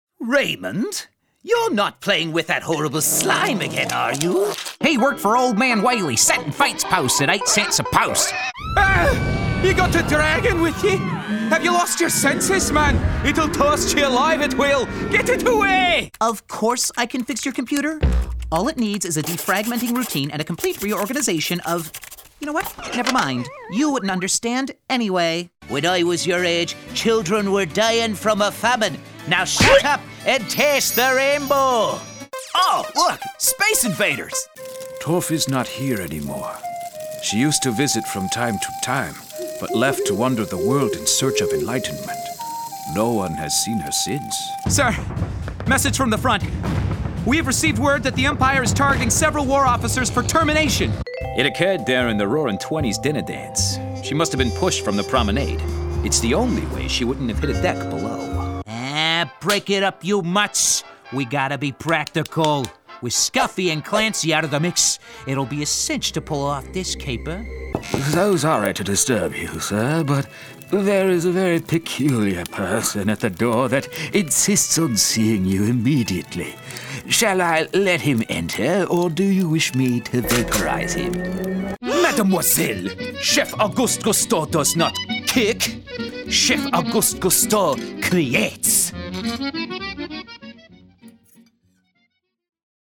Young Adult, Adult, Mature Adult
british rp | character
southern us | natural
standard us | natural
ANIMATION 🎬